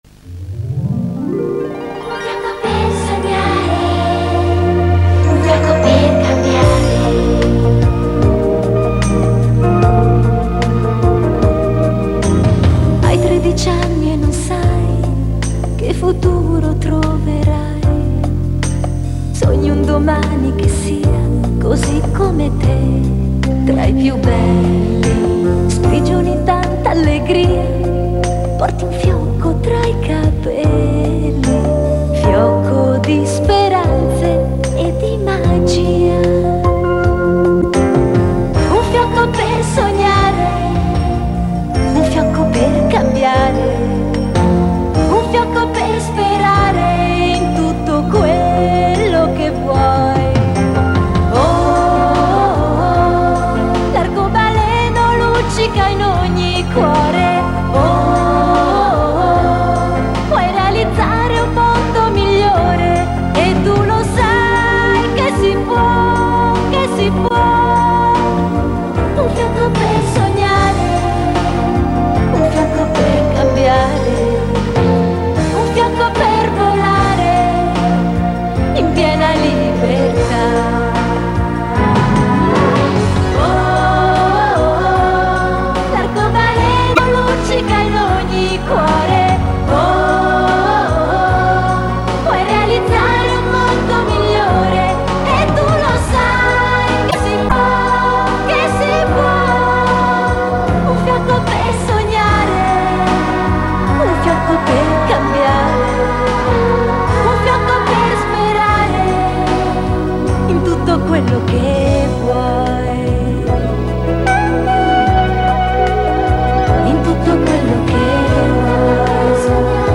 SIGLA: